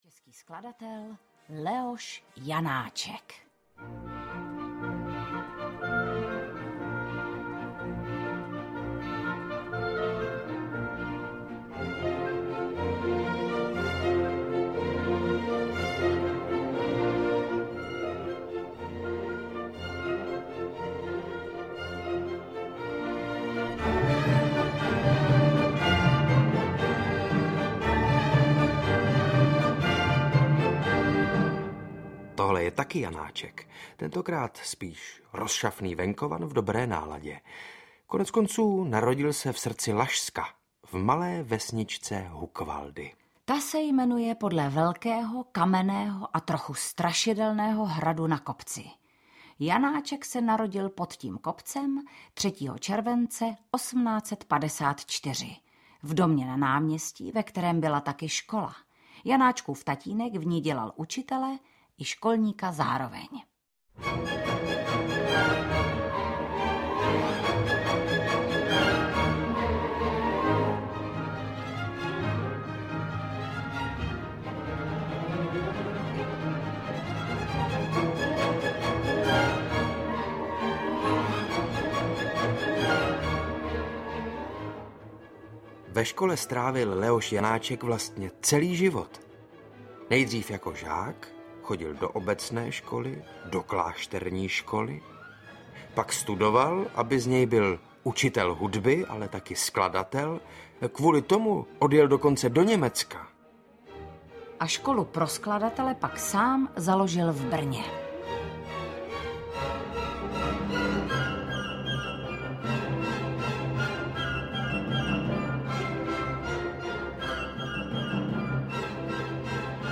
• InterpretSaša Rašilov mladší, Vanda Hybnerová